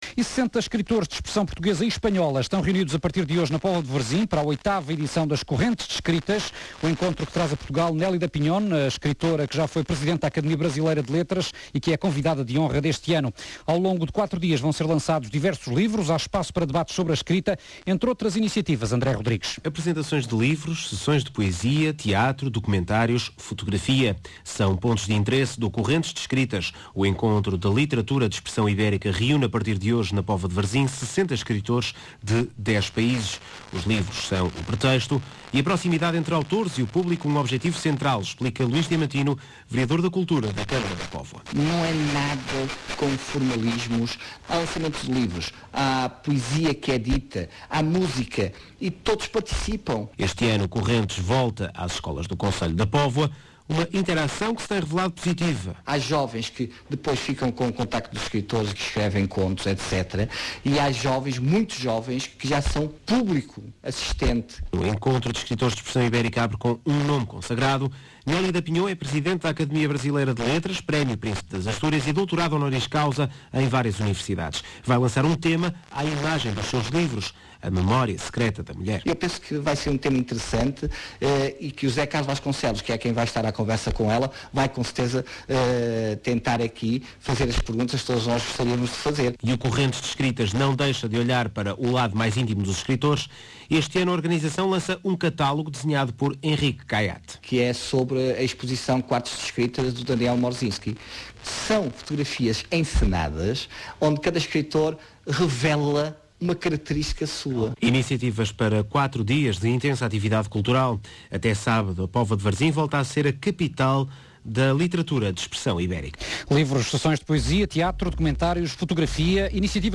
Notícia Rádio Renascença 2007_02_07 - C.M. da Póvoa de Varzim